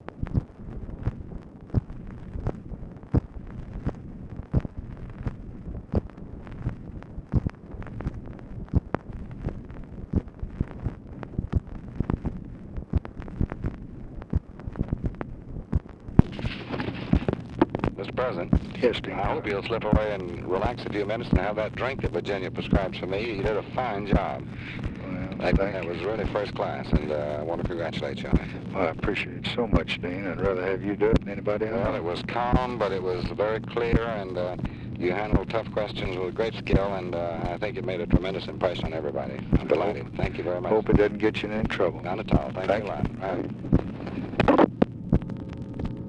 Conversation with DEAN RUSK, April 27, 1965
Secret White House Tapes